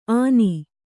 ♪ āni